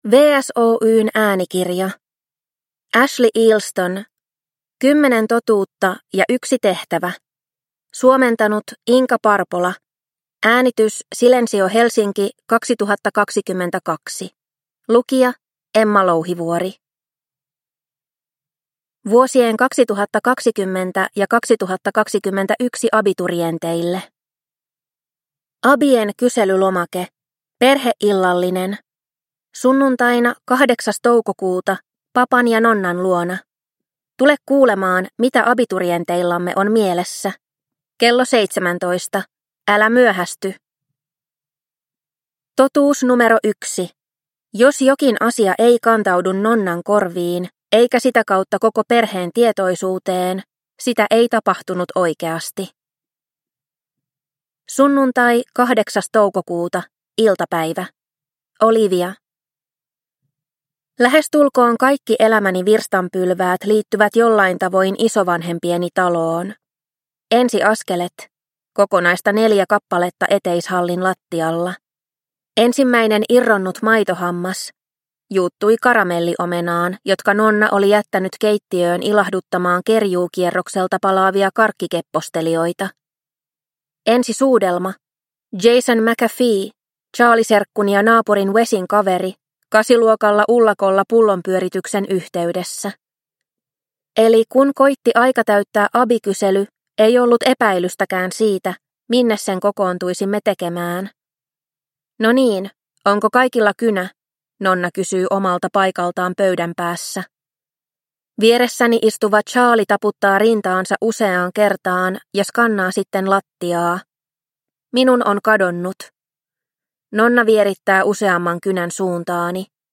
10 totuutta ja yksi tehtävä – Ljudbok – Laddas ner